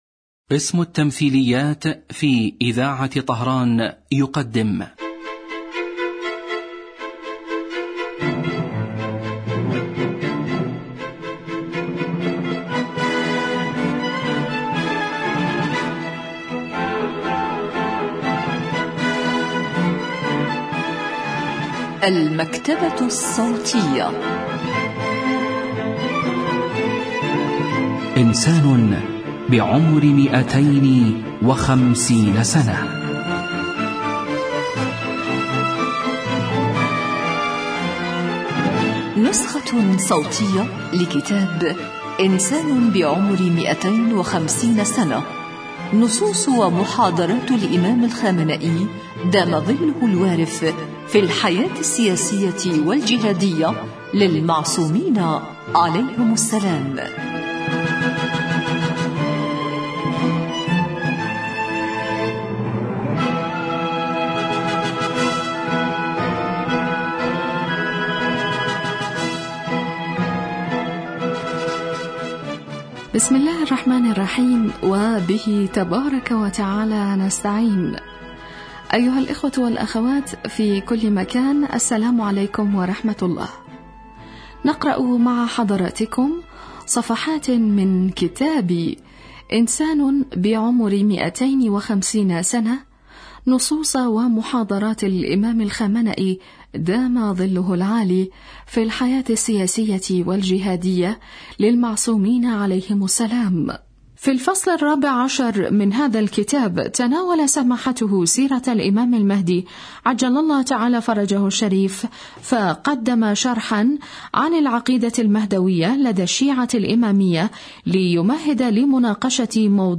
الكتاب الصوتي